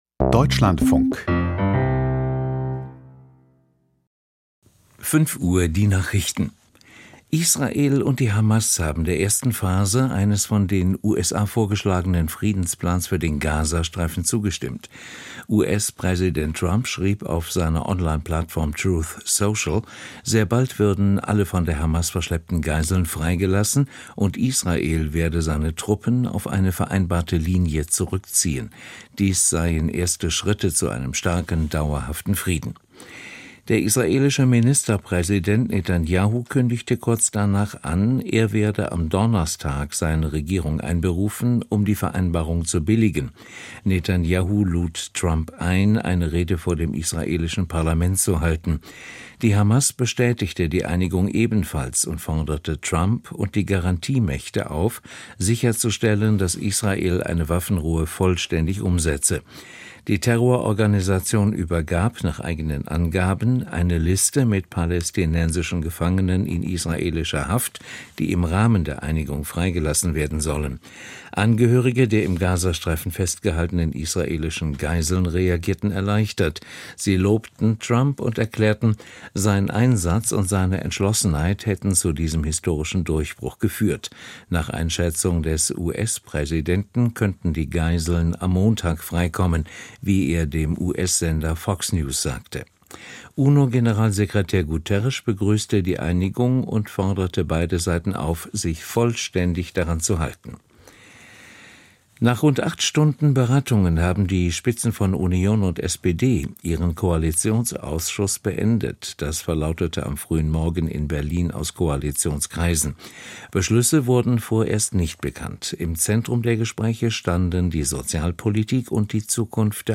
Die Nachrichten vom 09.10.2025, 05:00 Uhr
Aus der Deutschlandfunk-Nachrichtenredaktion.